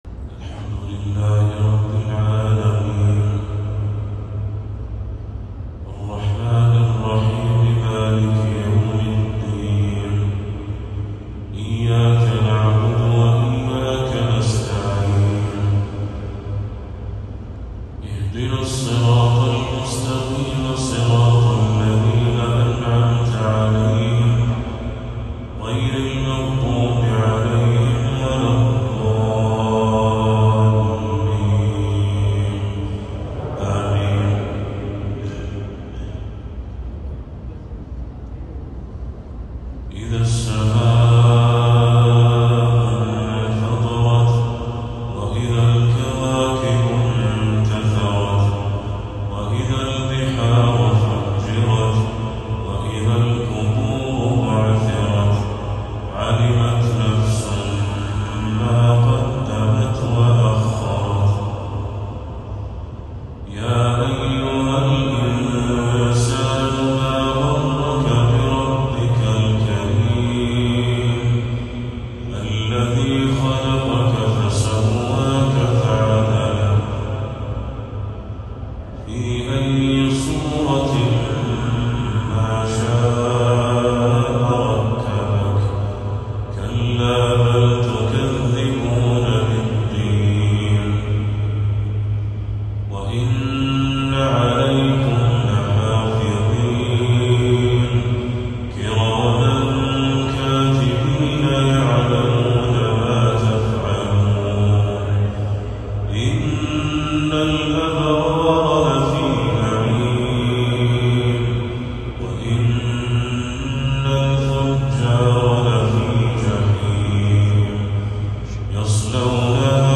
تلاوة لسورتي الانفطار والليل للشيخ بدر التركي | عشاء 30 صفر 1446هـ > 1446هـ > تلاوات الشيخ بدر التركي > المزيد - تلاوات الحرمين